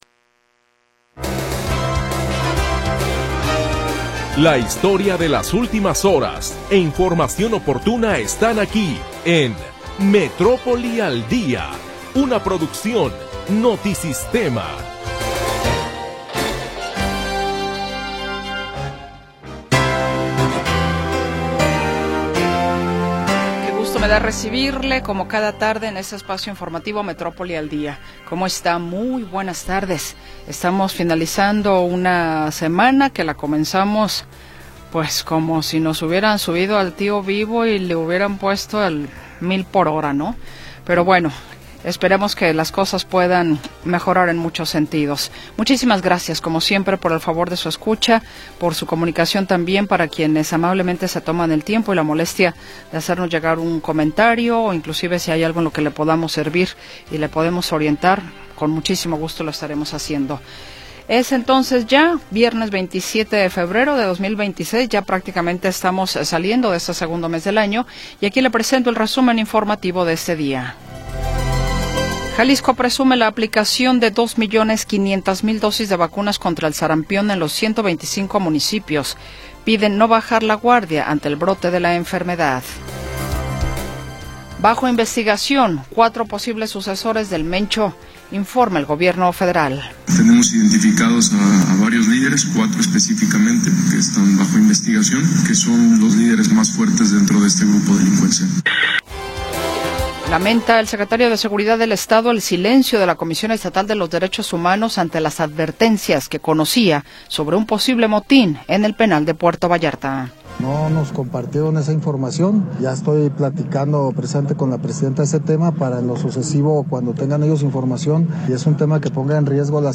La historia de las últimas horas y la información del momento.